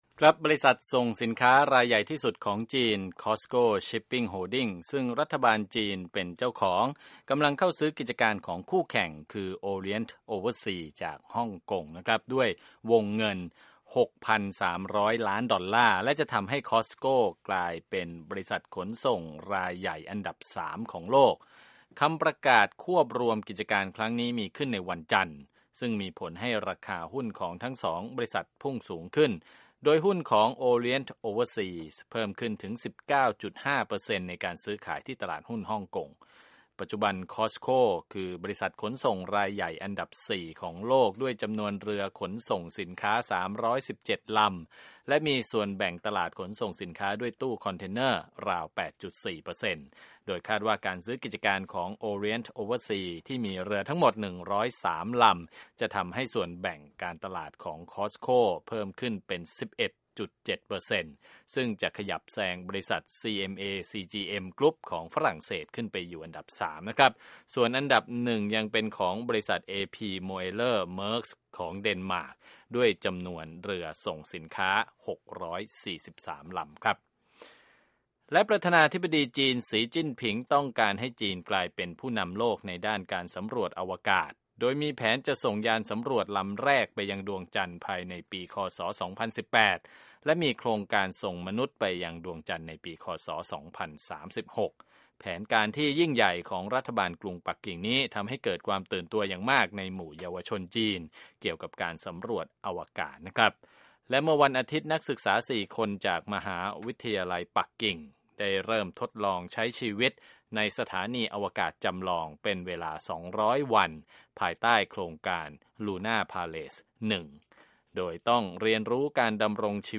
ข่าวธุรกิจ 7/10/2017